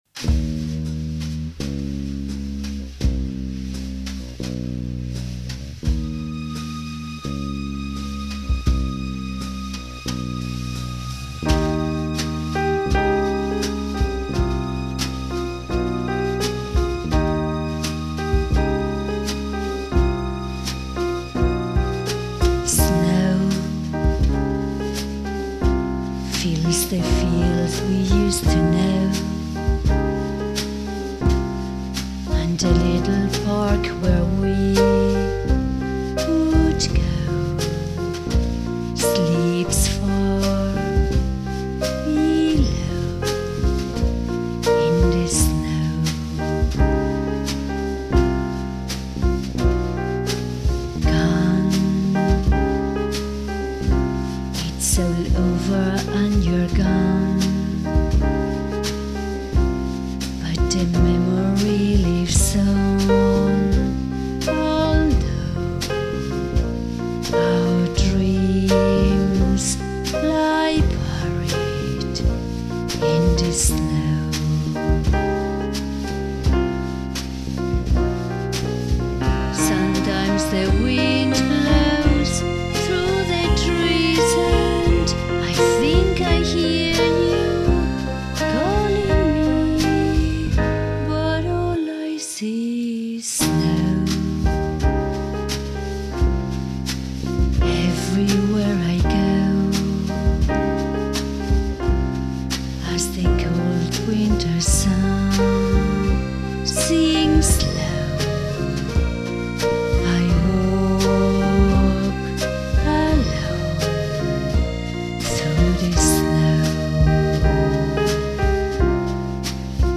bass
drums, percussion
guitars, keyboards, autoharp, vocal